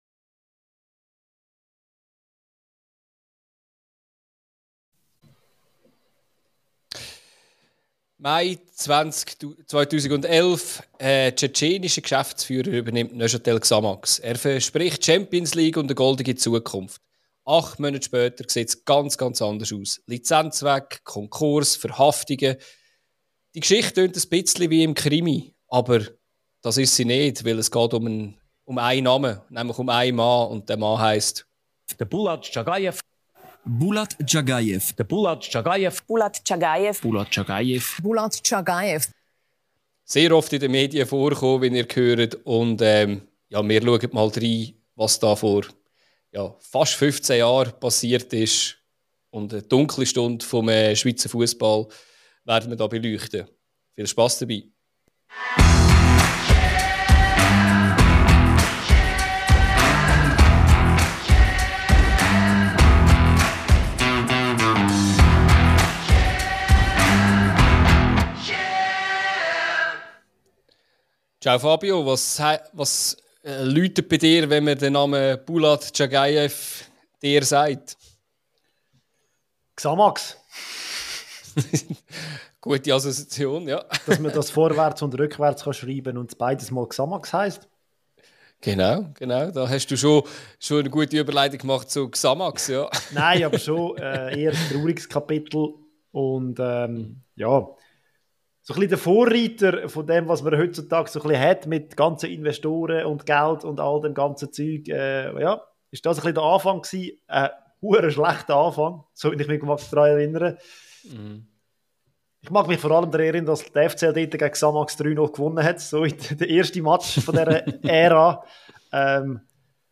Acht Monate später ist der Traditionsklub pleite, die Spieler sind freigestellt und der Präsident sitzt im Visier der Justiz. Wir sprechen über die wilden Monate an der Maladière, über gefälschte Bankgarantien, drohende Spielerstreiks und das juristische Nachspiel. Dazu gibt’s O-Töne, historische Clips und unsere Einordnung: Was hat dieser Fall über den Schweizer Fussball gezeigt – und warum lebt Xamax trotz allem noch immer?